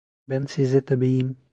Pronounced as (IPA) /siˈzɛ/